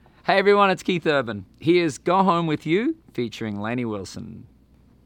LINER Keith Urban (Go Home With You) 2